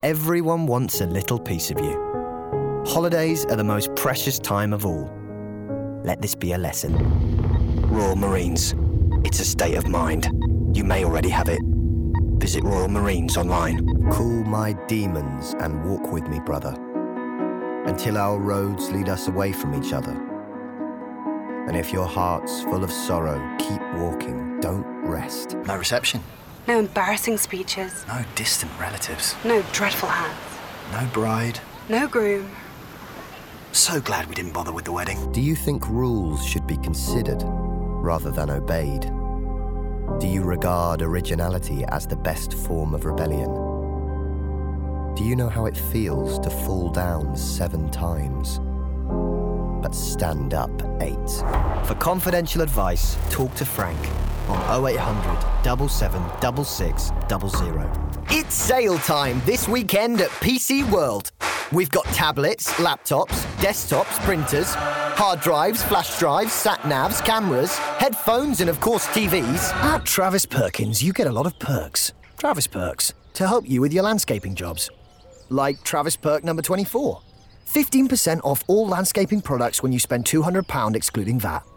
Male
Commercial Showreel
Straight
Commercial, Showreel